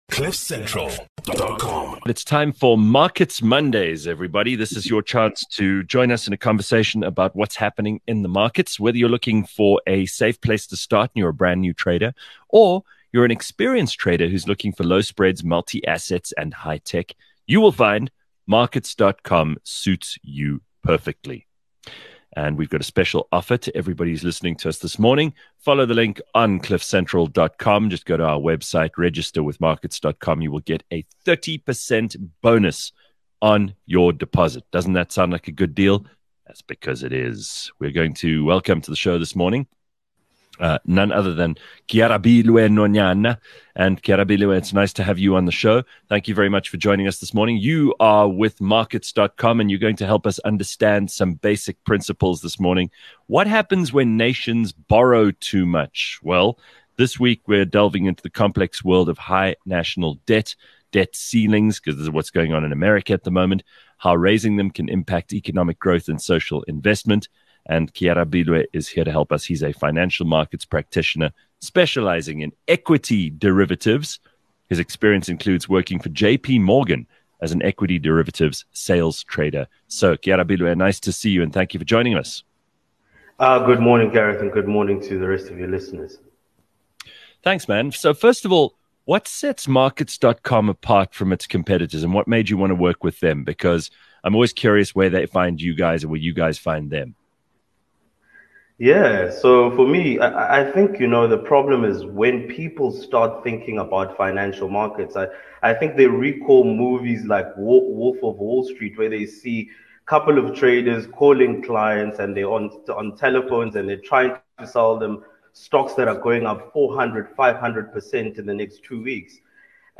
Don't miss this enlightening discussion on global debt and its real-life repercussions.